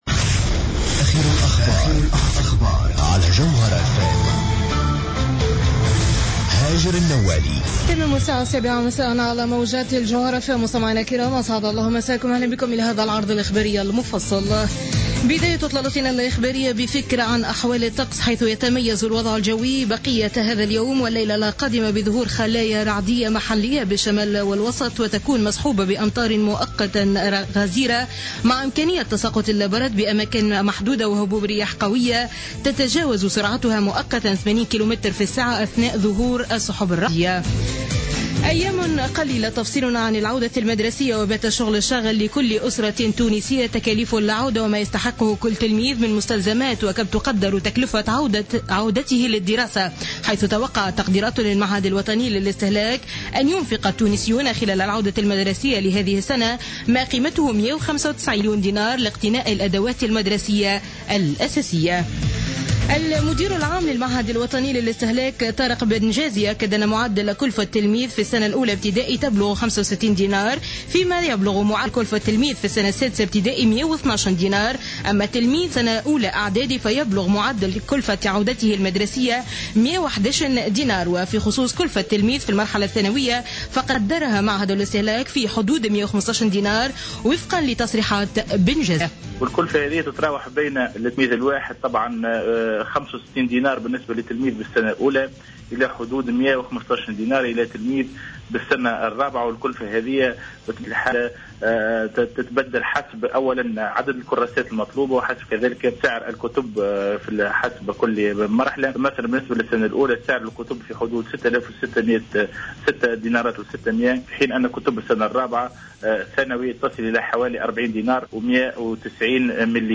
نشرة أخبار السابعة مساء ليوم الأحد 6 سبتمبر 2015